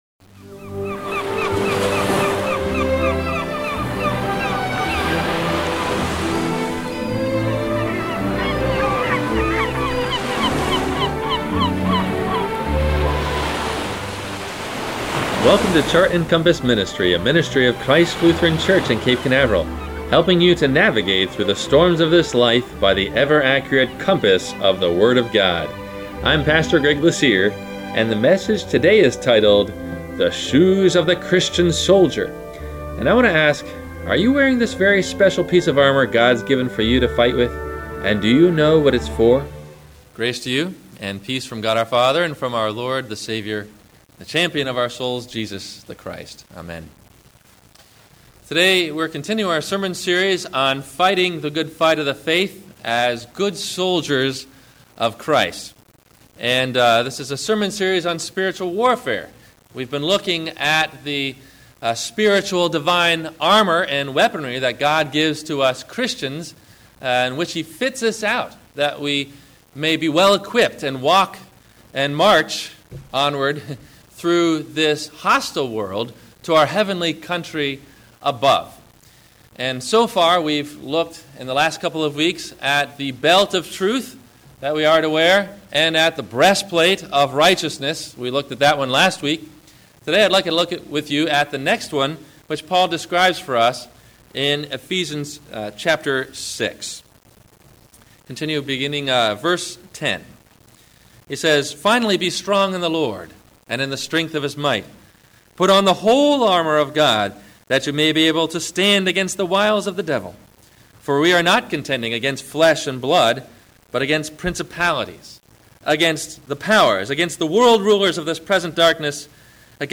The Shoes of the Christian Soldier – Spiritual Warfare – WMIE Radio Sermon – August 25 2014